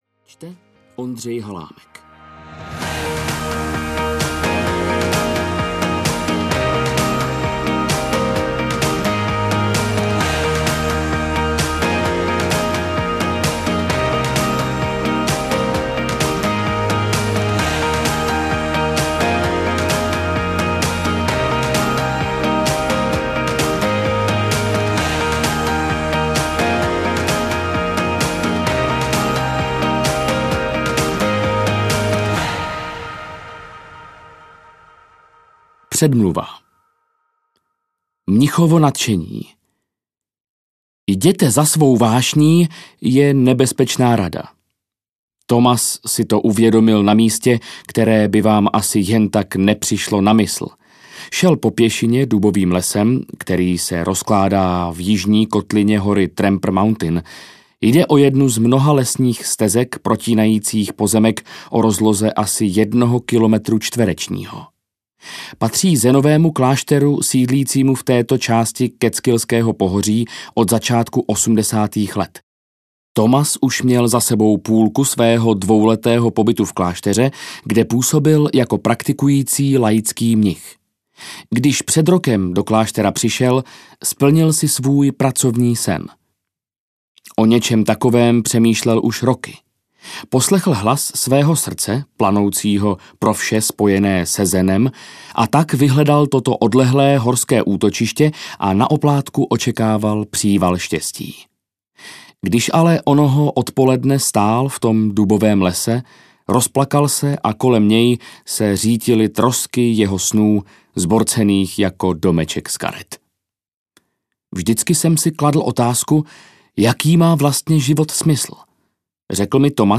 Tak dobří, že vás nepřehlédnou audiokniha
Ukázka z knihy